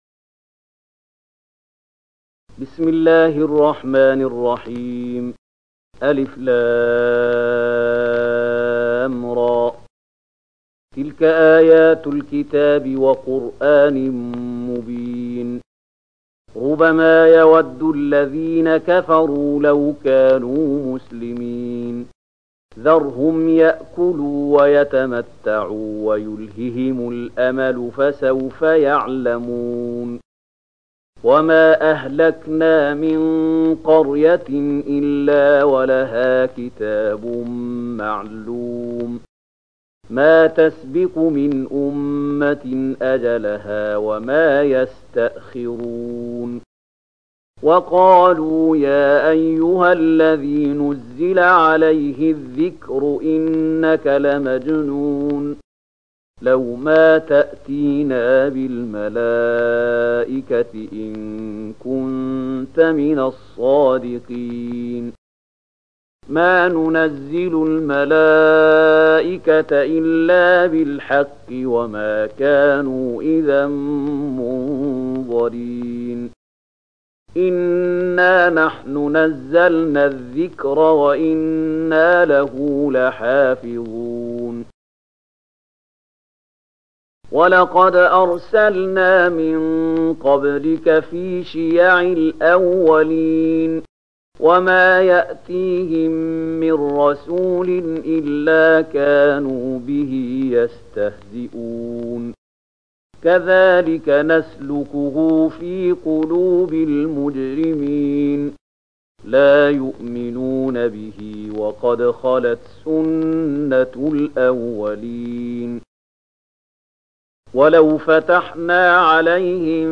015 Surat Al-Hijr ayat 1-15 dengan bacaan murattal Syaikh Mahmud Khalilil Hushariy: